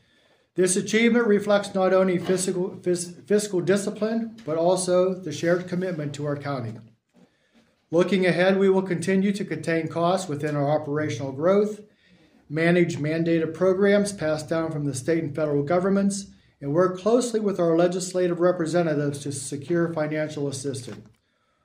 Commissioners were facing a $2.9 million open balance for the budget at the start of February, and started to work on it at that time.  Commissioners Chairman Mike Keith said that they were able to knock that down to $386,968.